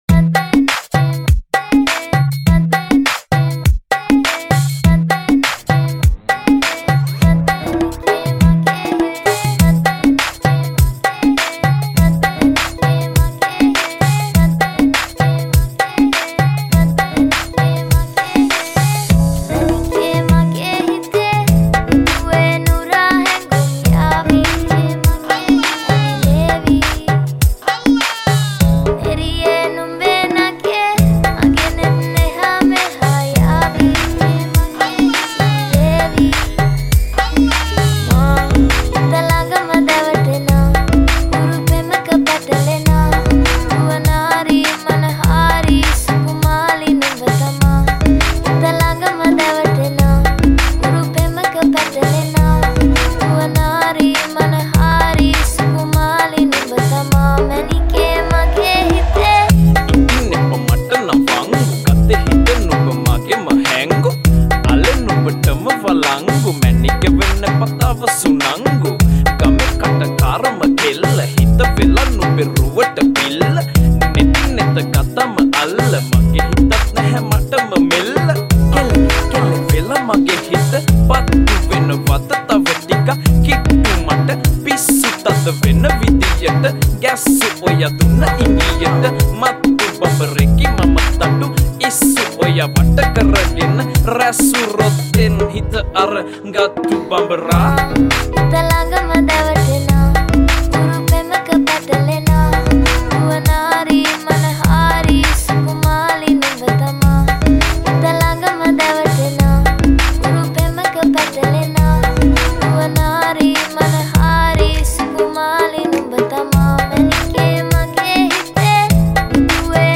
[ 101 bpm ] أغنية هندية